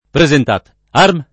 vai all'elenco alfabetico delle voci ingrandisci il carattere 100% rimpicciolisci il carattere stampa invia tramite posta elettronica codividi su Facebook presentat’ arm [ pre @ ent # t! # rm! ] escl. (mil.) — come s. m., anche presentatarm [ pre @ entat # rm ]